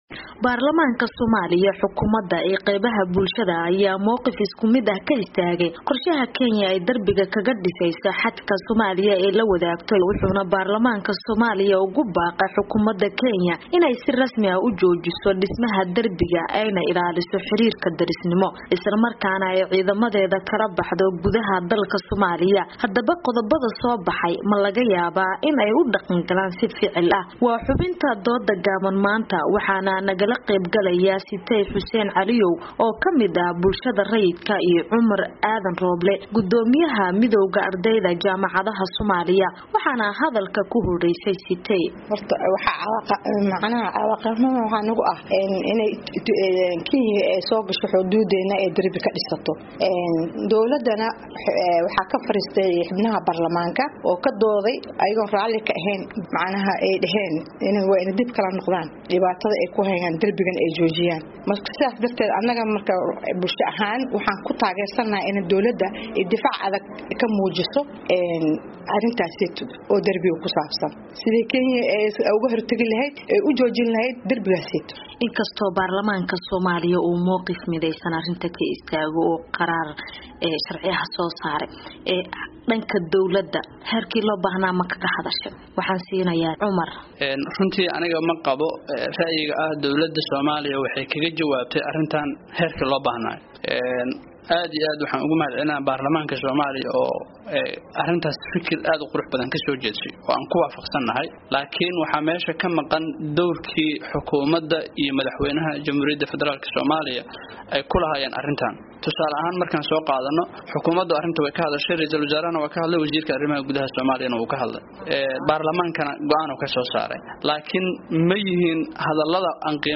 Falanqeyn: Go'aanka Baarlamanka Ay Kasoo Saareen Darbiga Kenya
oo aqoonyahanno ay kala qeyb galayaan.